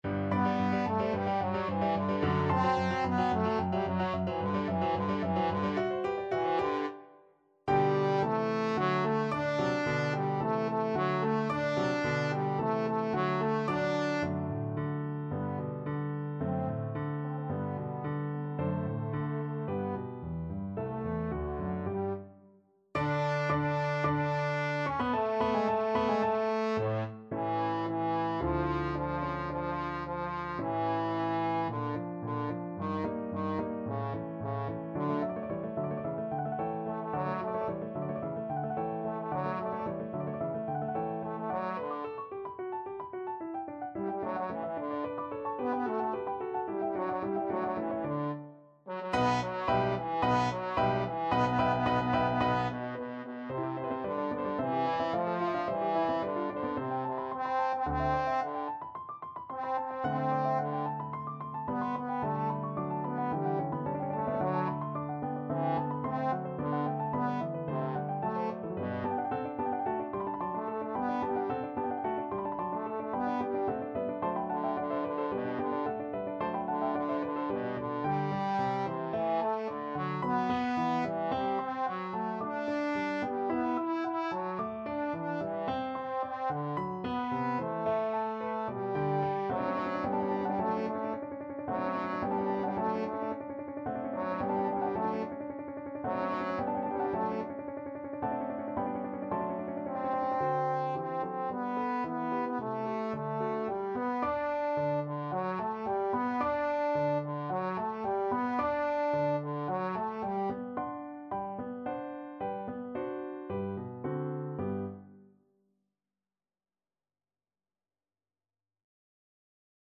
4/4 (View more 4/4 Music)
Allegro assai =220 (View more music marked Allegro)
Classical (View more Classical Trombone Music)